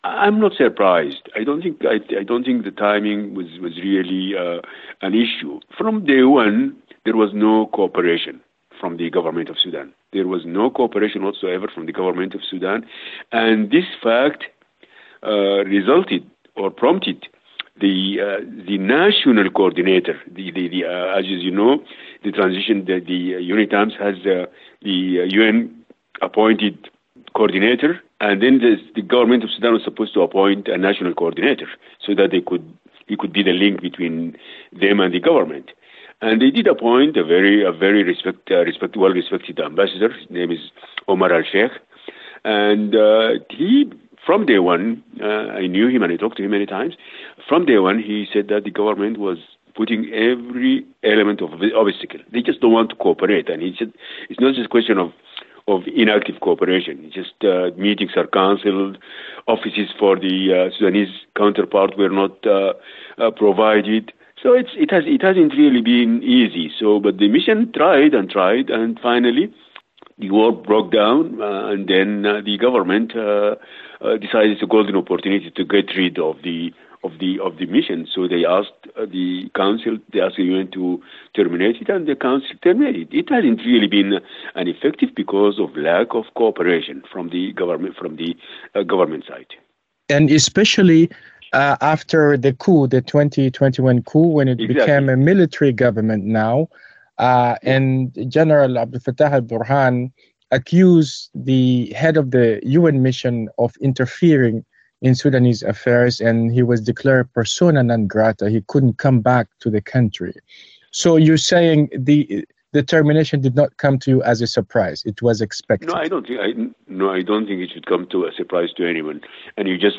Embed Analysis: UNSC Dissolves UNITAMS Amid Sudan's Conflict [5:34] Embed The code has been copied to your clipboard.